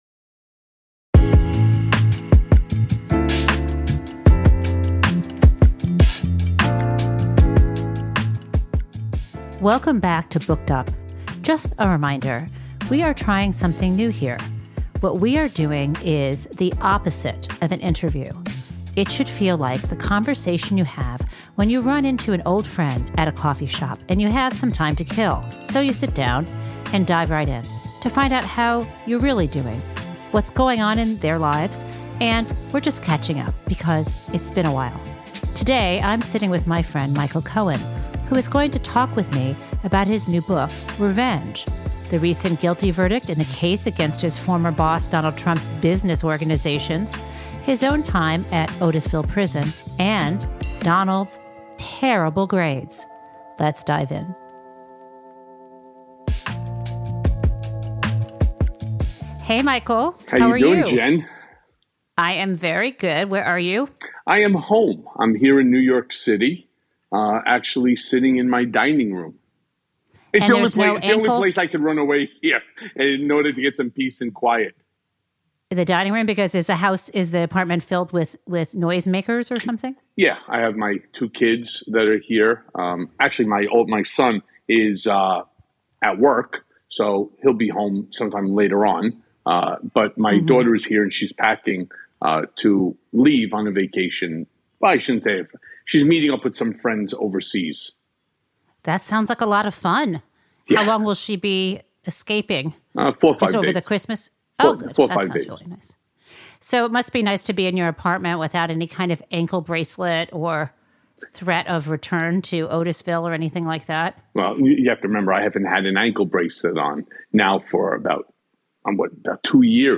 He is now a top-selling author and host of the popular Mea Culpa podcast which, as of our Booked Up interview, had 65 million downloads.